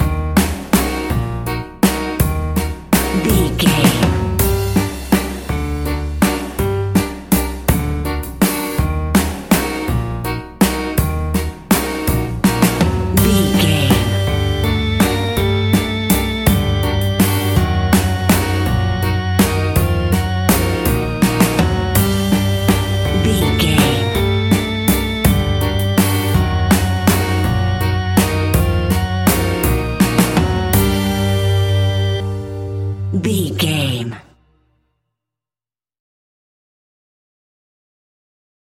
Aeolian/Minor
scary
ominous
eerie
piano
synthesiser
drums
electric organ
strings
instrumentals
horror music